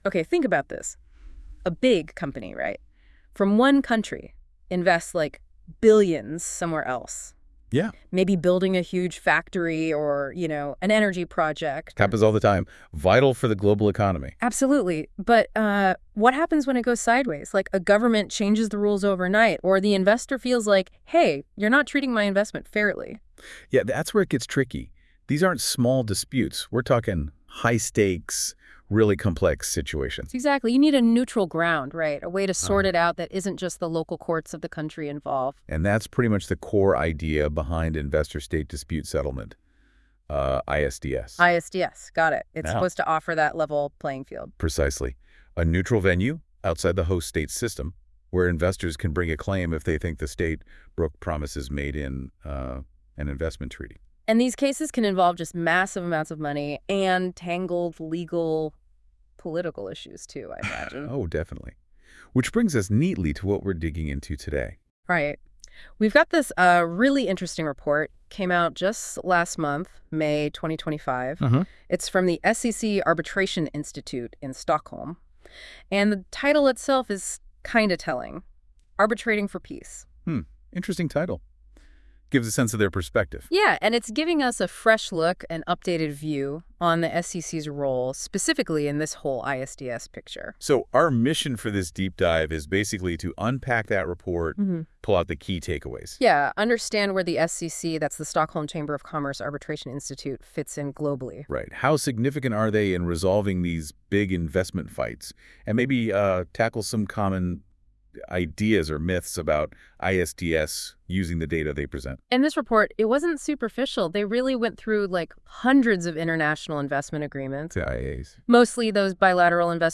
Check out our AI-generated podcast covering this SCC report. Please note that ‘ICSID’ is pronounced incorrectly throughout the episode.